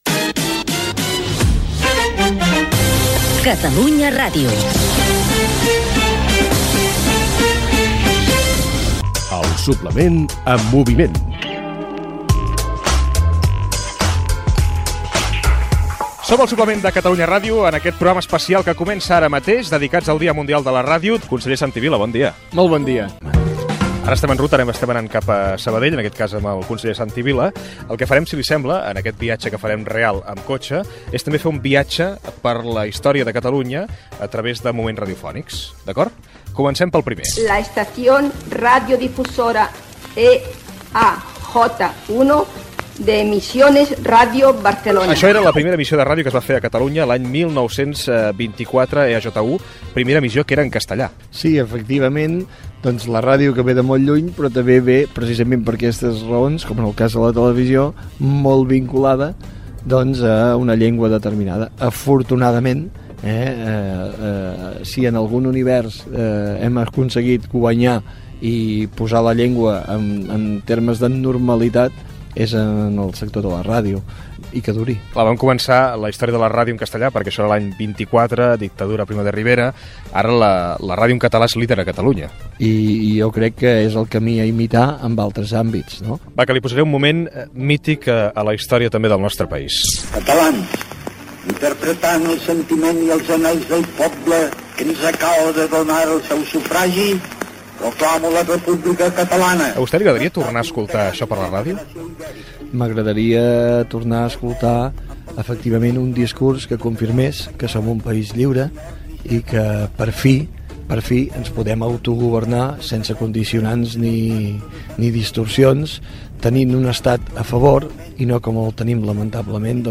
Indicatiu de l'emissora i del programa. Inici del programa especial fet amb motiu del Dia Mundial de la ràdio de 2017. Ricard Ustrell entrevista, en moviment, al conseller de la Generalitat Santi Vila. Mentre viatgen cap a Sabadell fan un recorregut per àudios de diferents moments radiofònics.